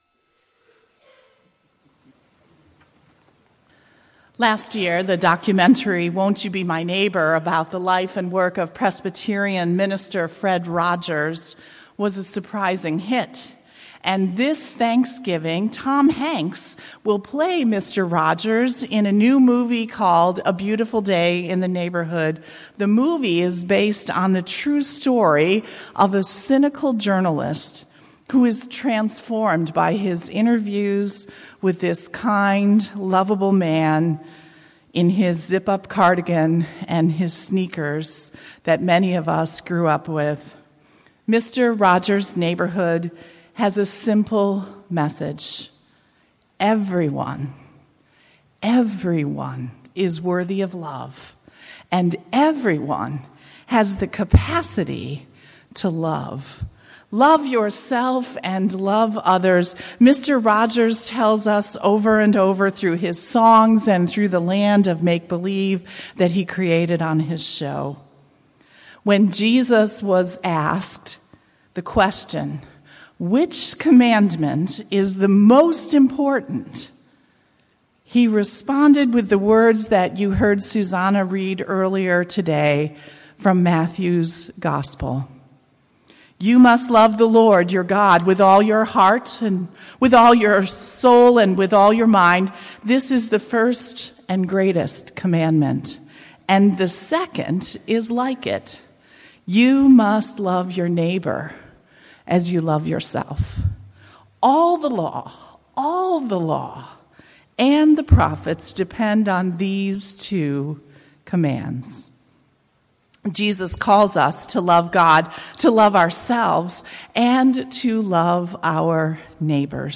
2019-09-08 Sermon • Nardin Park Church
Sept0819-Sermon.mp3